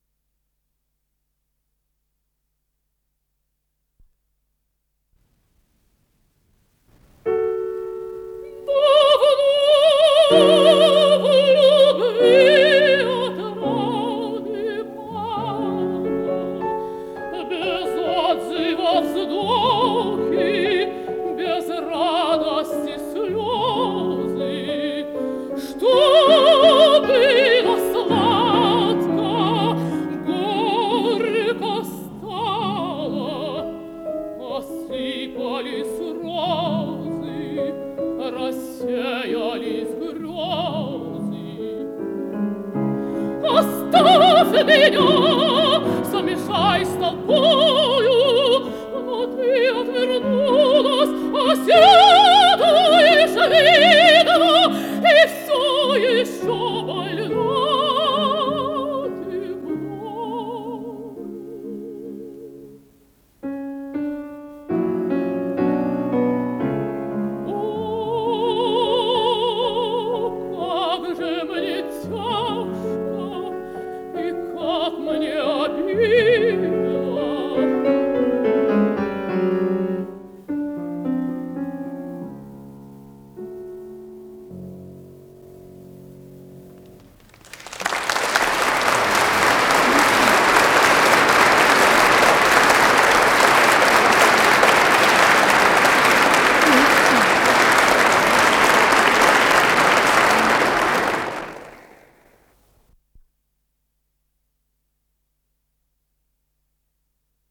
с профессиональной магнитной ленты
ИсполнителиЕлена Образцова - пение
фортепиано